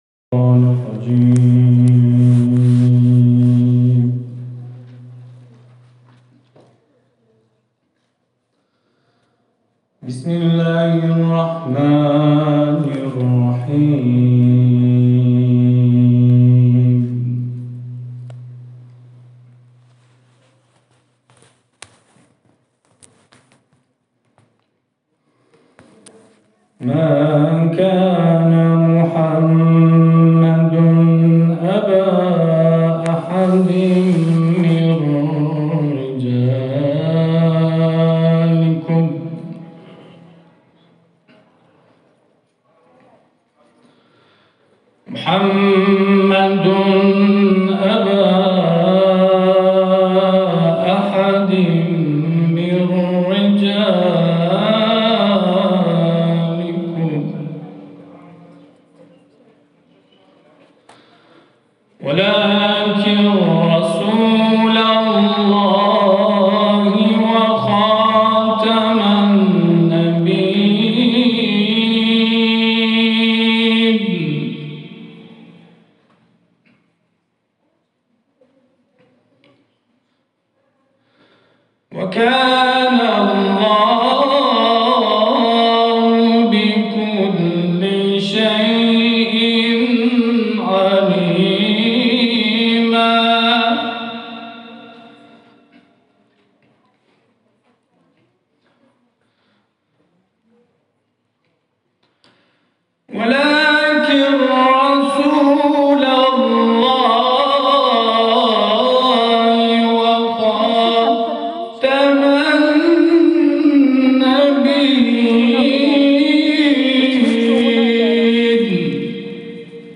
تلاوت ، سوره احزاب